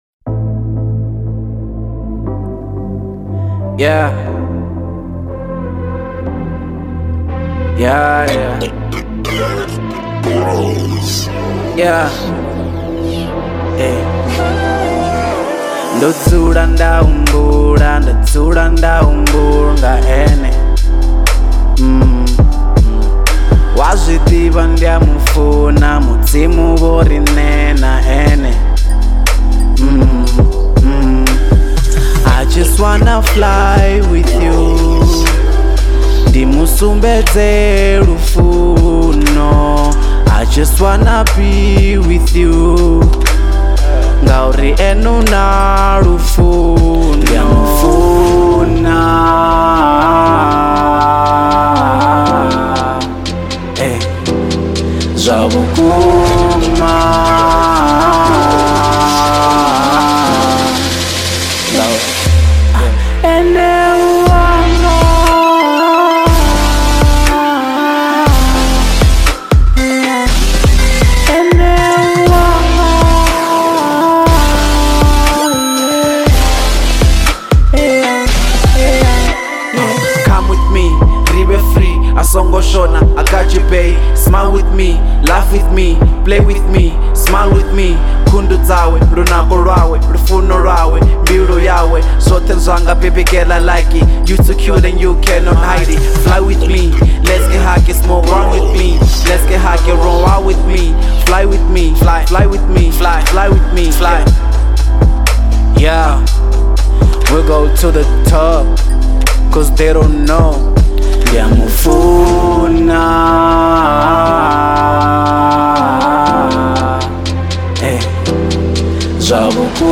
02:46 Genre : Venrap Size